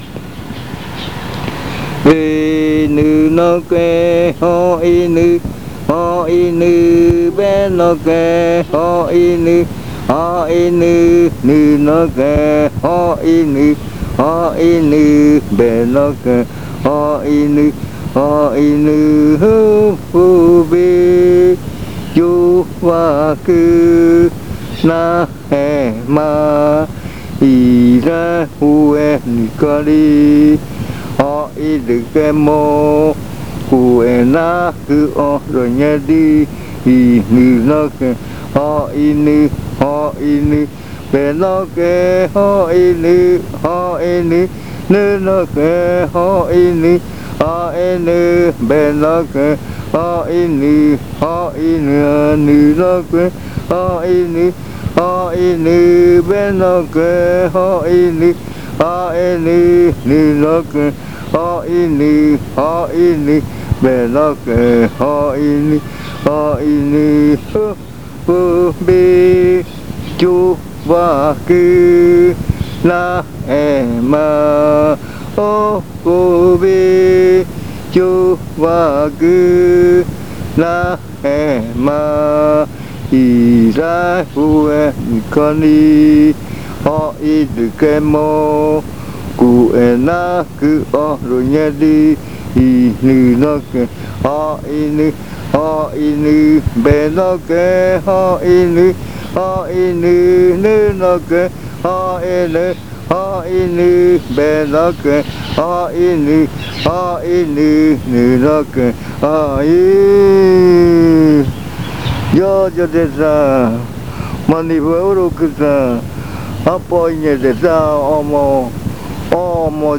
Se canta en la madrugada. Sencillamente, una canción que se canta para que los bailarines bailen.
Simply, a chant that is sung for the dancers to dance to. The chant is related to lulling in the hammock.